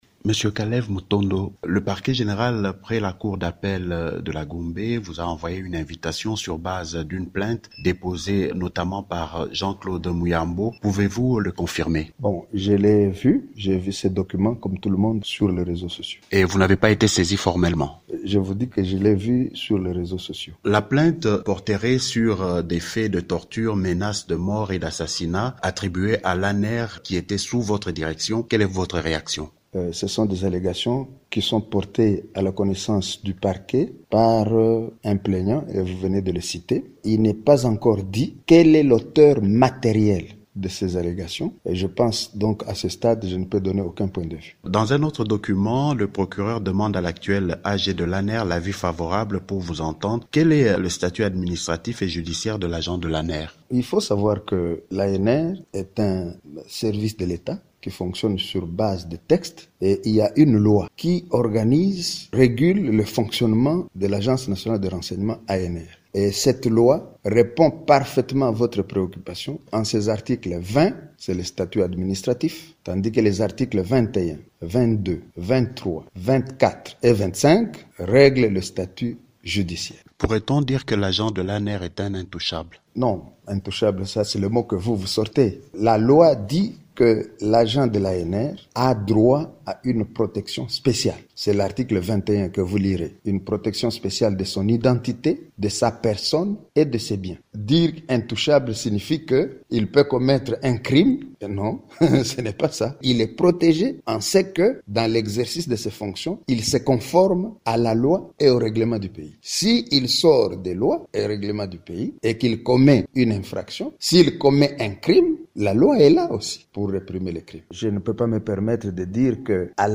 Interview :